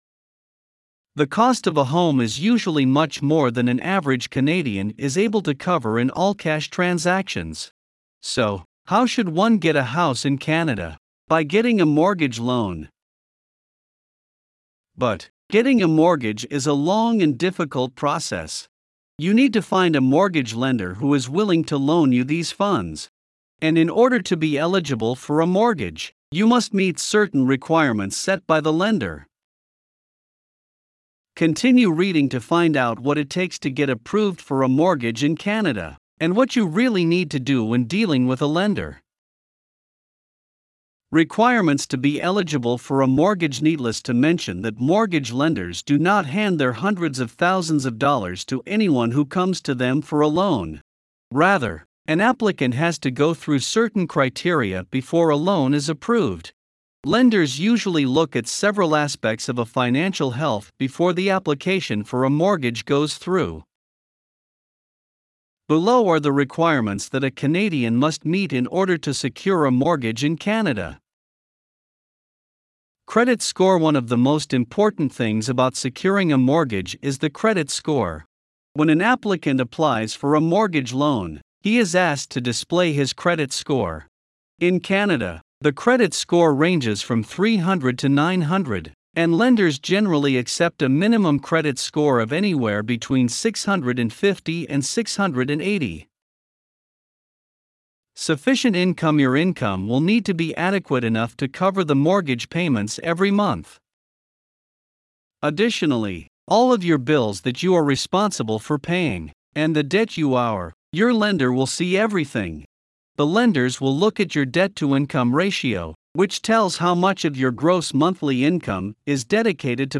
Voiceovers-Voices-by-Listnr_17.mp3